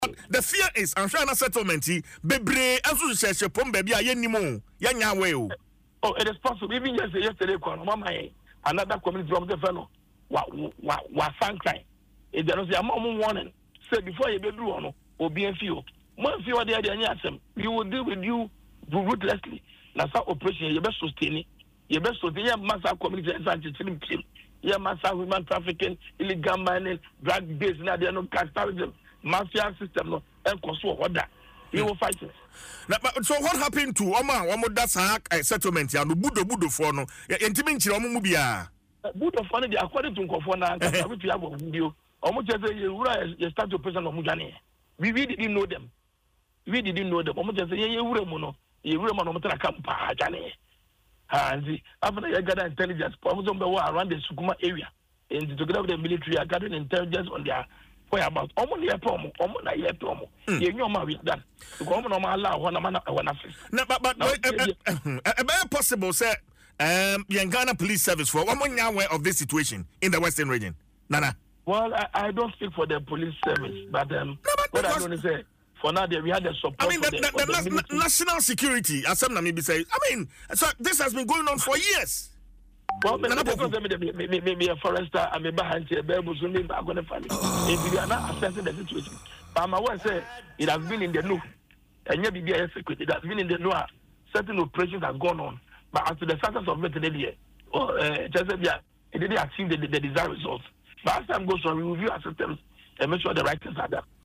made the disclosure on Adom FM’s morning show Dwaso Nsem.